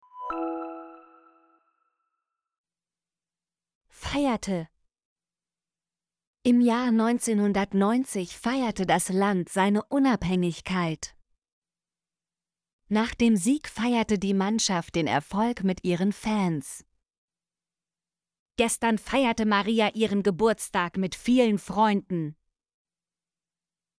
feierte - Example Sentences & Pronunciation, German Frequency List